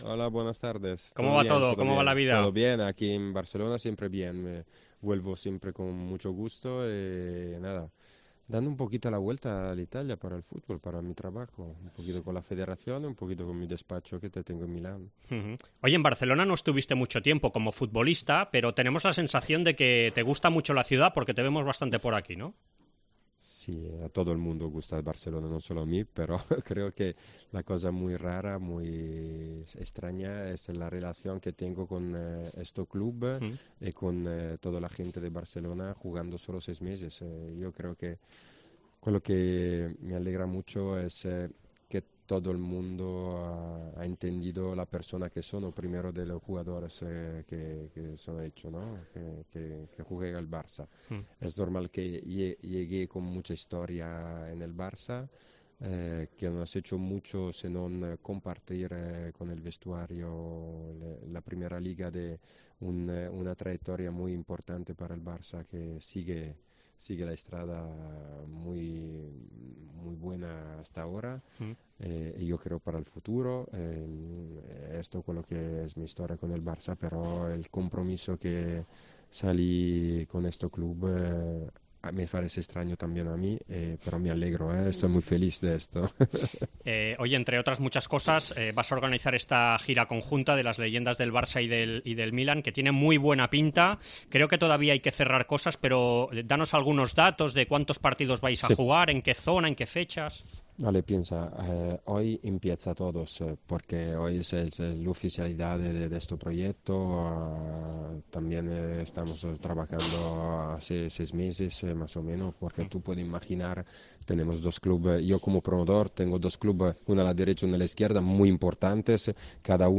AUDIO: Entrevista amb Demetrio Albertini, ex jugador de FC Barcelona i Atlético de Madrid entre d'altres